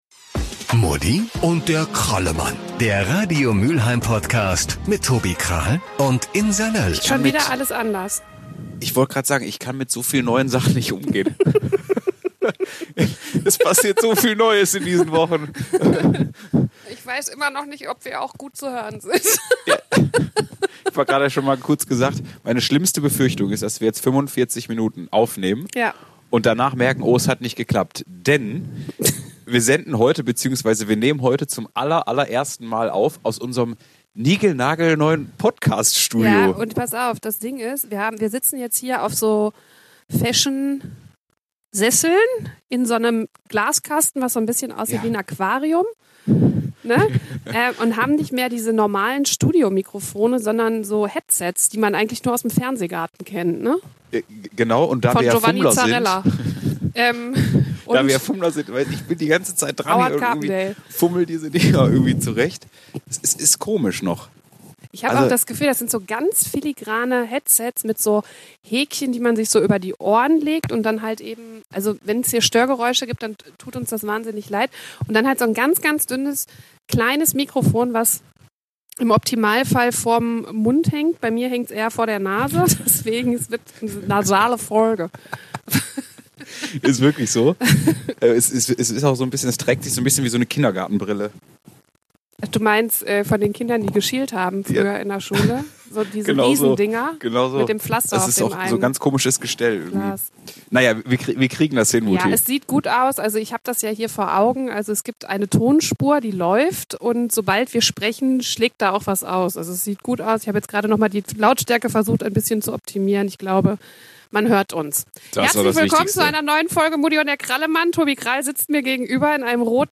Ansonsten läuft es wie immer beim ersten Mal: es ist Luft nach oben - vor allem bei der Akustik. Sorry dafür!